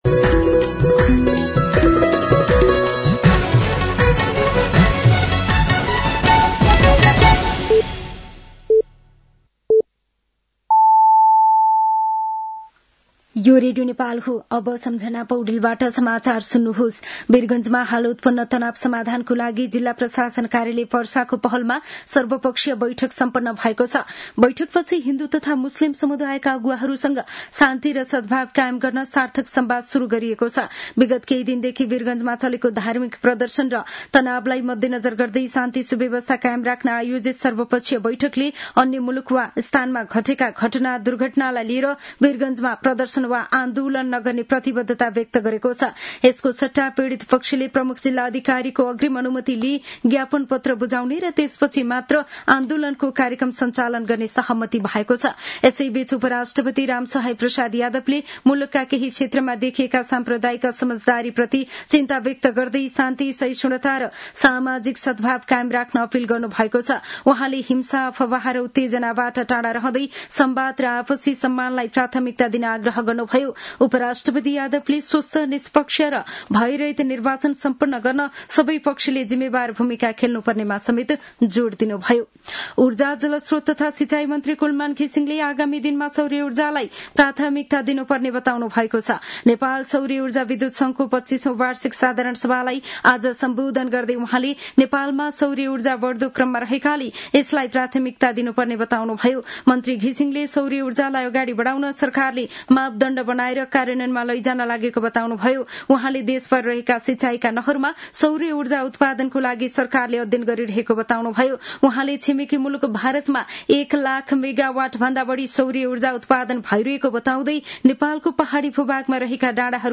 साँझ ५ बजेको नेपाली समाचार : २२ पुष , २०८२